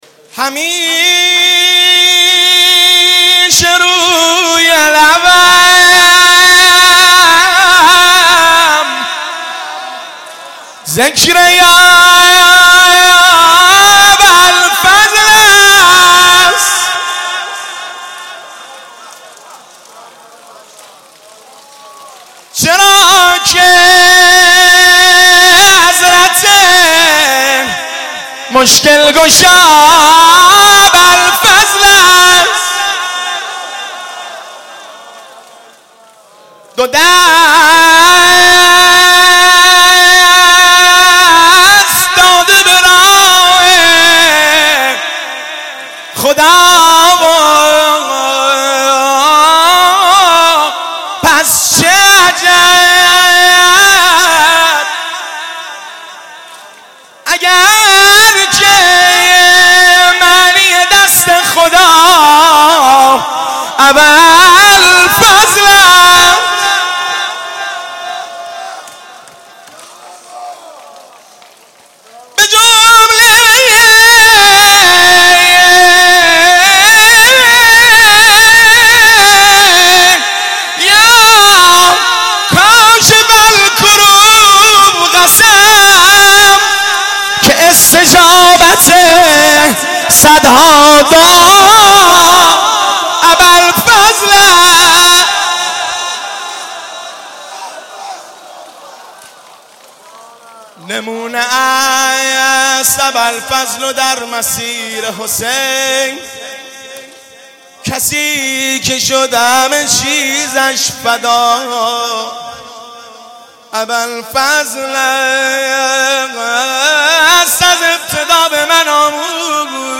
شعر خوانی